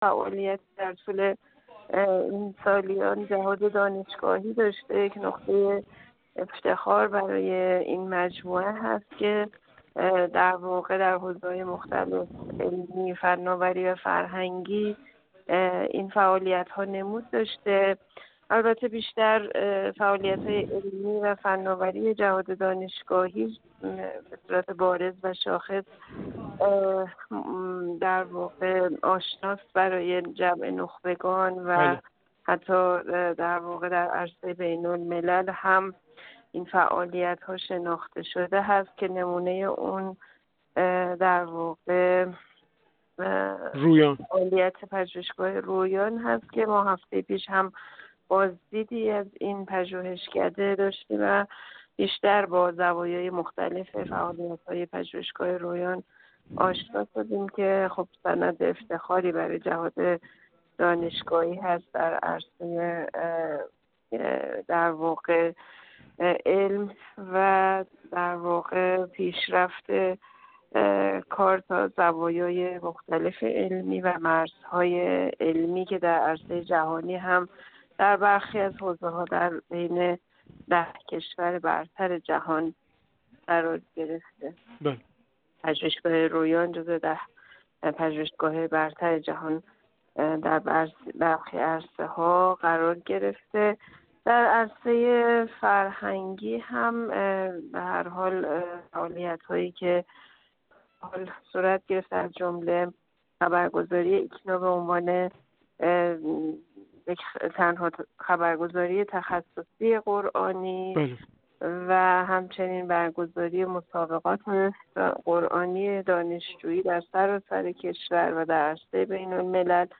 زهره الهیان، عضو کمیسیون امنیت ملی و سیاست خارجی مجلس
زهره الهیان، عضو کمیسیون امنیت ملی و سیاست خارجی مجلس شورای اسلامی، در گفت‌وگو با ایکنا درباره ارزیابی کلی خود از عملکرد جهاددانشگاهی گفت: مجموعه فعالیت‌های جهاددانشگاهی از زمان تأسیس تا امروز یک نقطه افتخار برای این مجموعه و کشور است که در حوزه‌های علمی، فناوری و فرهنگی نمود داشته است.